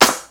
CC - Rare Style Snare.wav